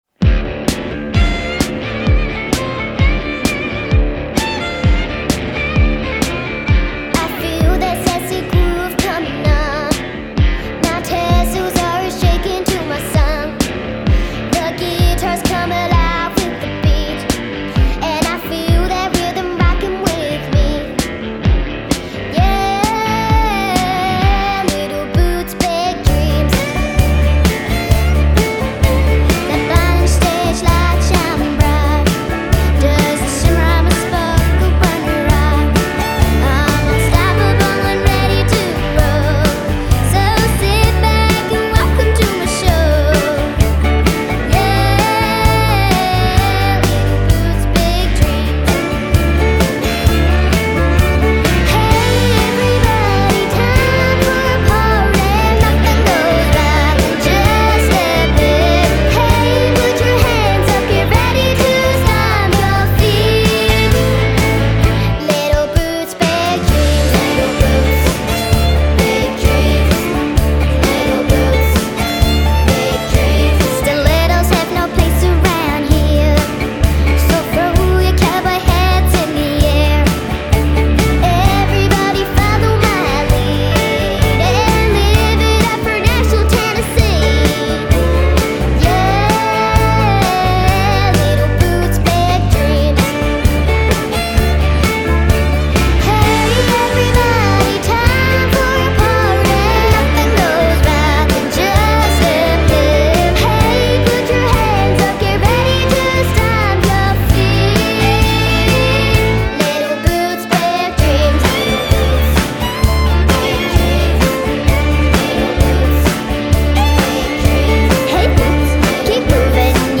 The girls perform it with sass, energy and love.